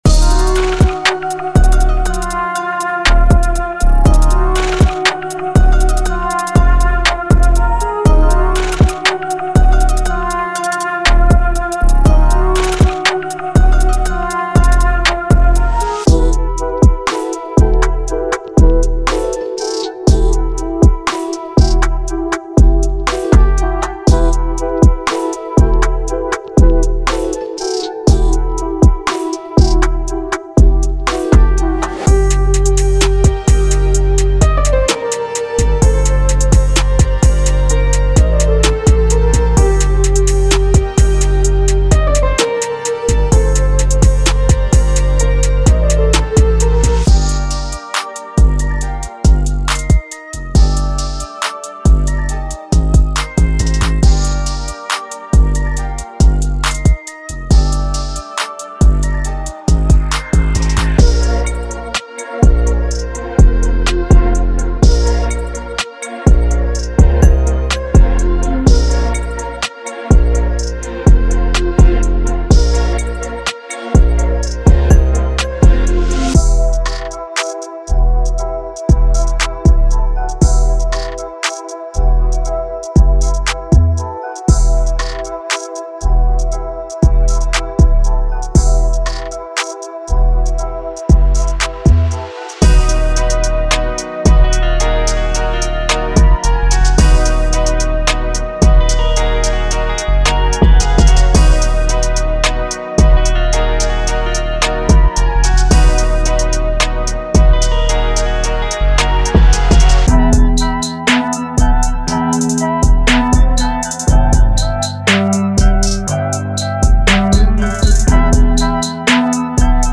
Type: Midi Templates Samples
Hip-Hop / R&B Trap
• 10 Electric Guitar Loops